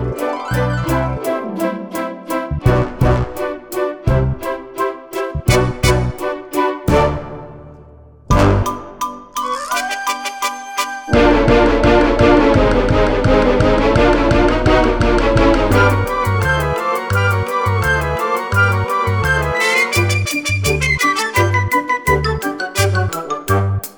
no Backing Vocals Soundtracks 3:42 Buy £1.50